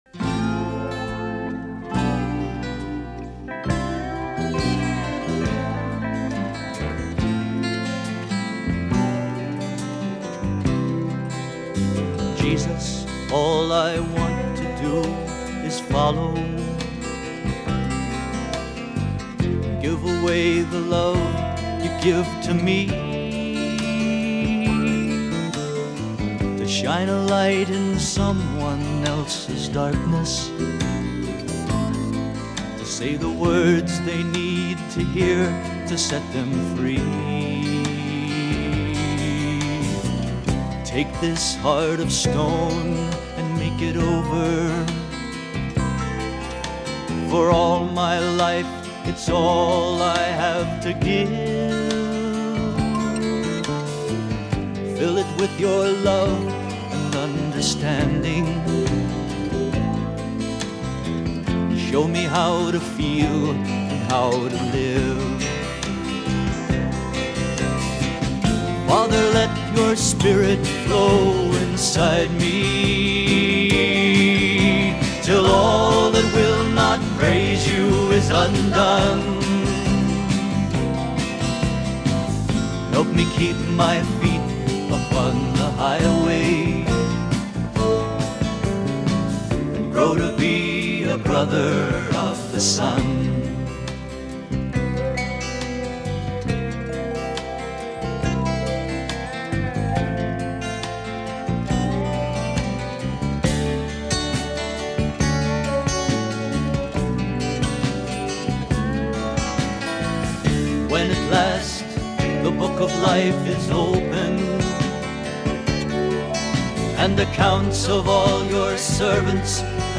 Christian